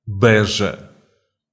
The Beja District (Portuguese: Distrito de Beja; Portuguese pronunciation: [ˈbɛʒɐ]